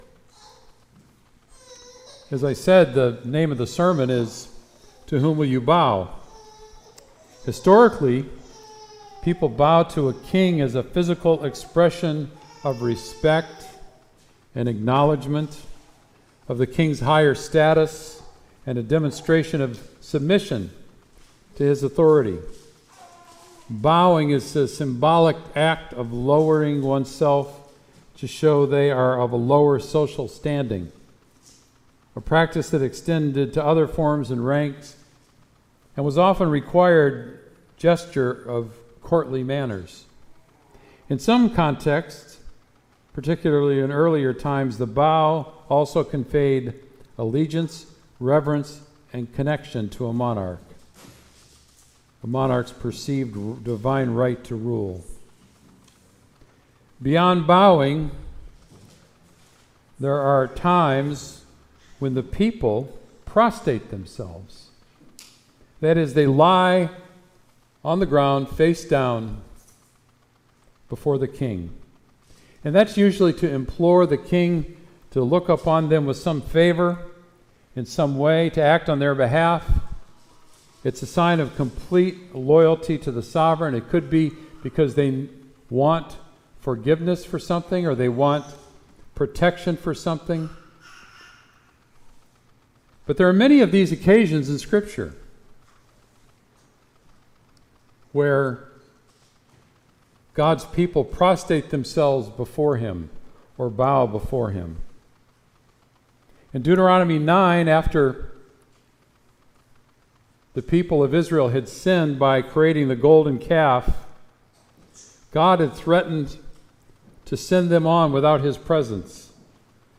Sermon “To Whom Will You Bow?”